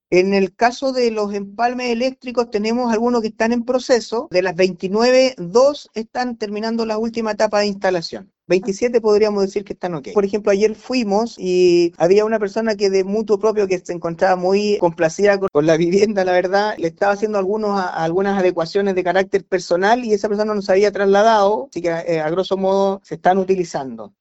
El alcalde (s), Cristian López, indicó que “en el caso de los empalmes eléctricos tenemos a algunos que están en proceso. De las 29, dos están terminando la última etapa de instalación y 27 están ok”.